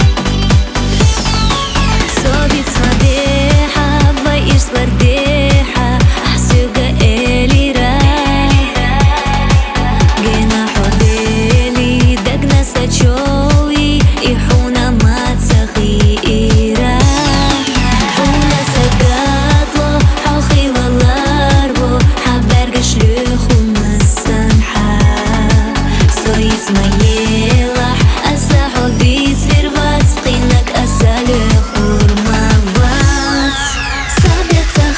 Жанр: Русские